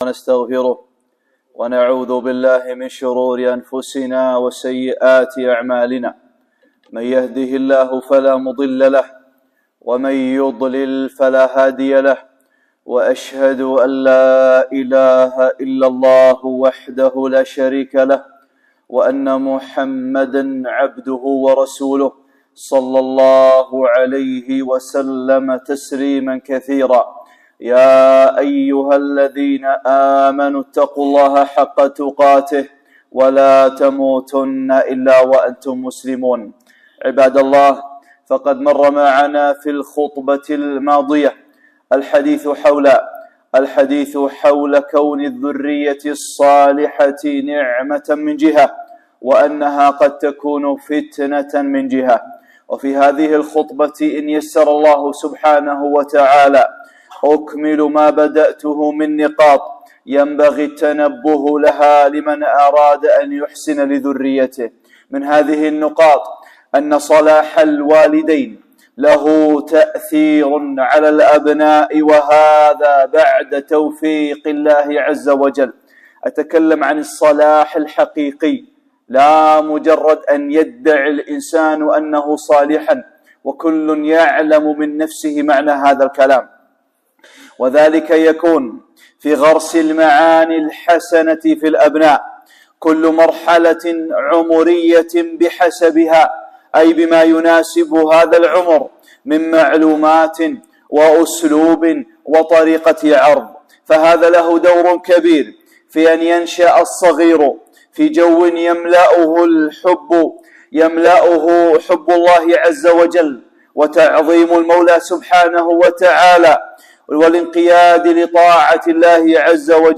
(8) خطبة - أثر صلاح الآباء والأمهات